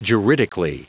Prononciation du mot juridically en anglais (fichier audio)
Prononciation du mot : juridically
juridically.wav